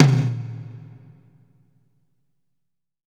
Index of /90_sSampleCDs/Sampleheads - New York City Drumworks VOL-1/Partition A/KD TOMS
GATE      -L.wav